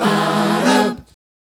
Ba Dah 152-E.wav